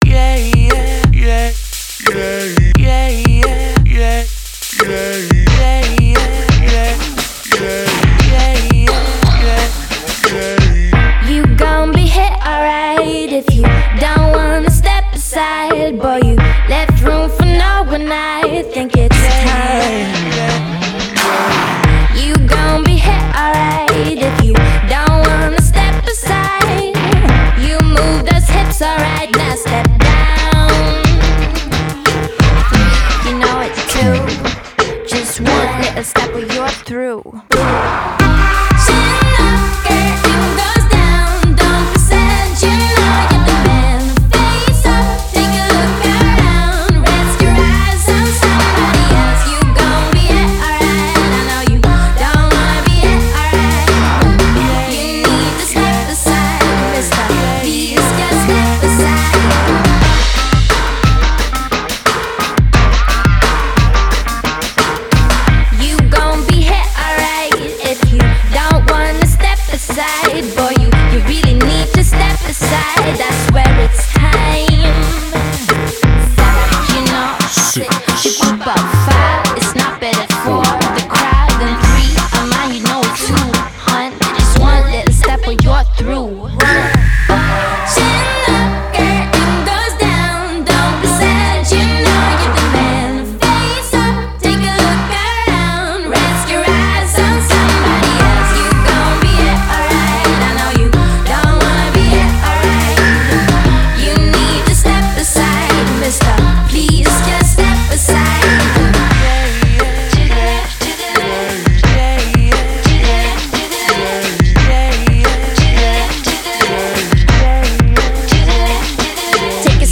Genre: Pop, Electro-Pop